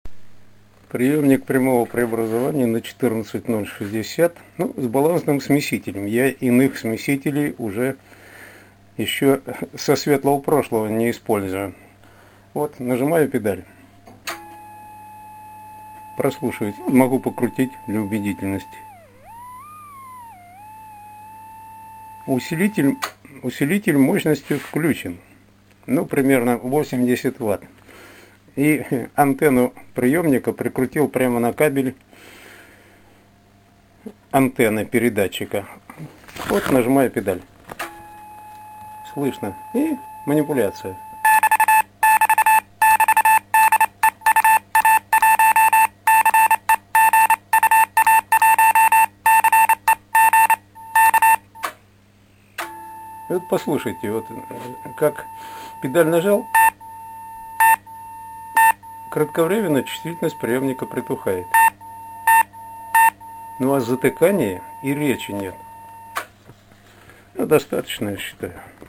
Ещё посмотрел, как мои аппараты прямого преобразования переносят мощные QRM, своего 80- ваттного передатчика. Отлично переносят.